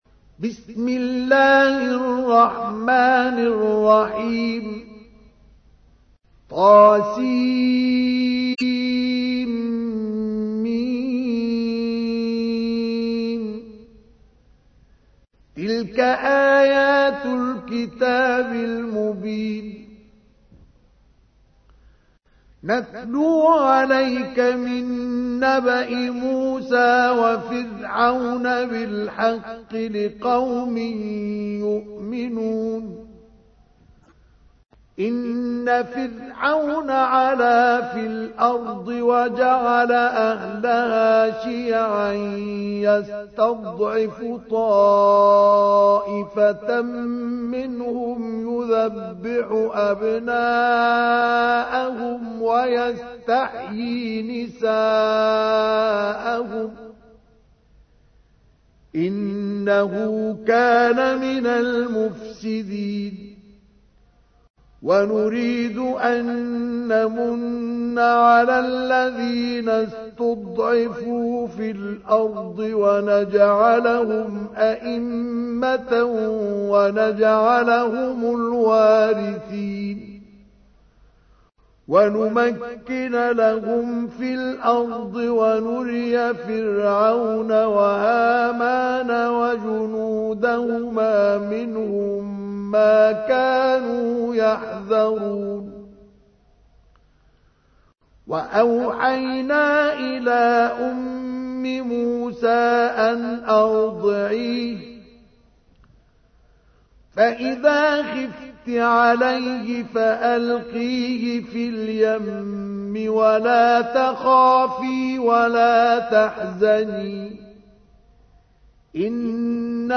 تحميل : 28. سورة القصص / القارئ مصطفى اسماعيل / القرآن الكريم / موقع يا حسين